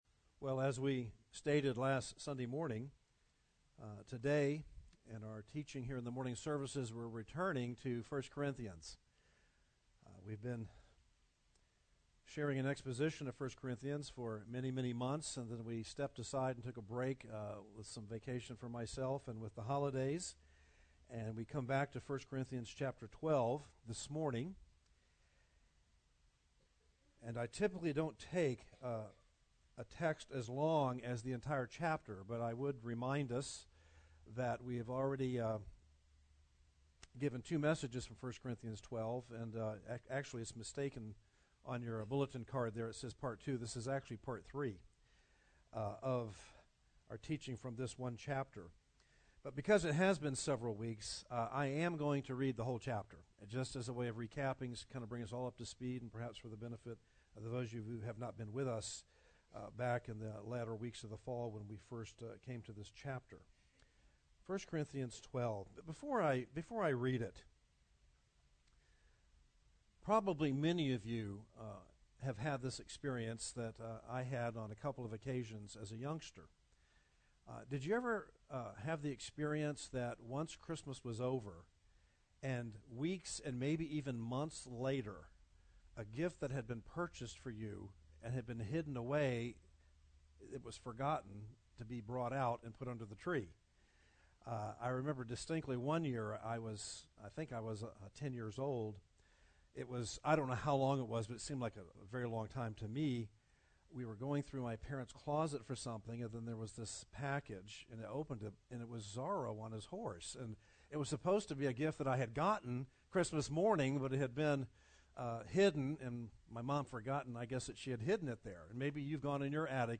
The Divine Design of the Church - Part 2 » The Chapel Church of Gainesville, Florida